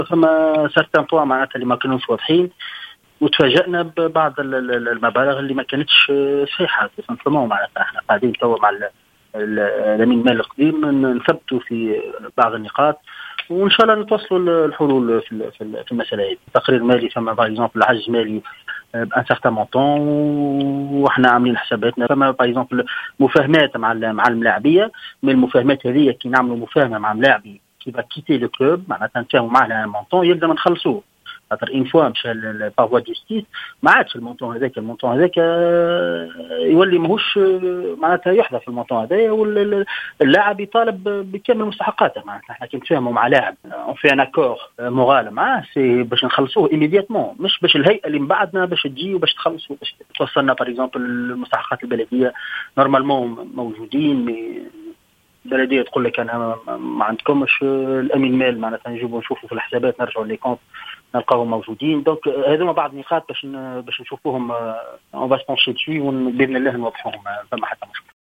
تصريح لجوهرة أف أم